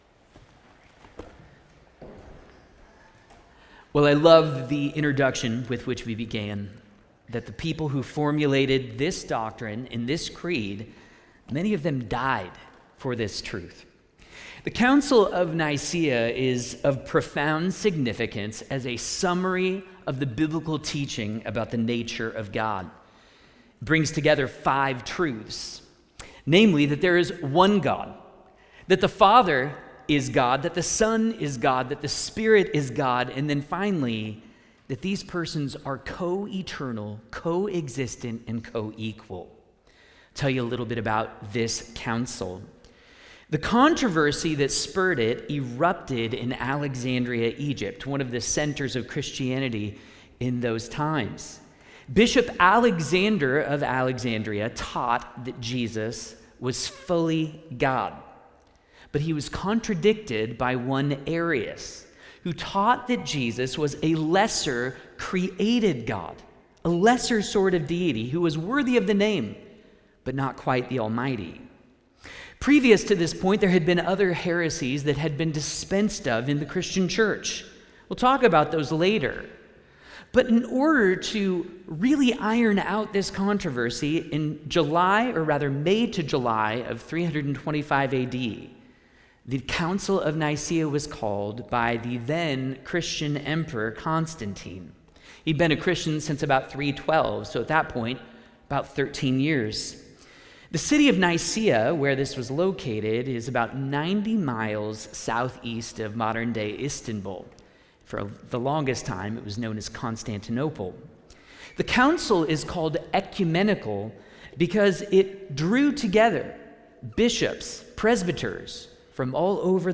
Nicene Creed 1700th anniversary celebration with guest lecturer